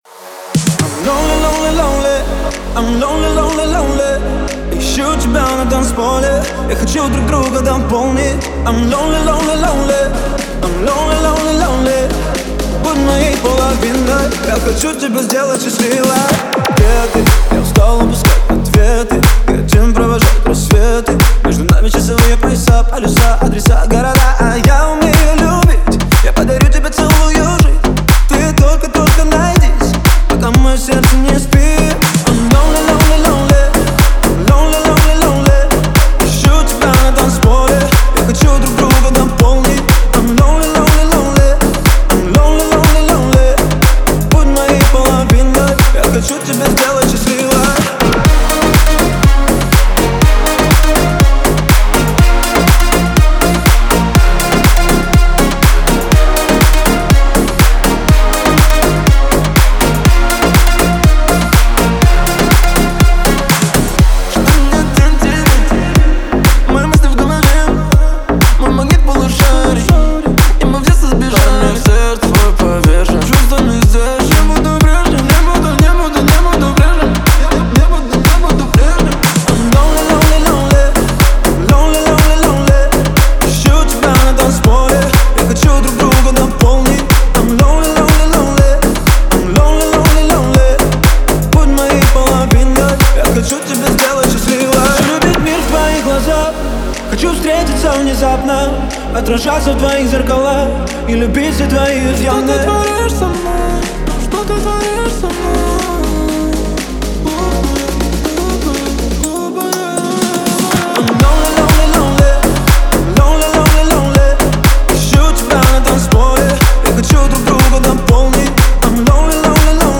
это яркий трек в жанре поп с элементами EDM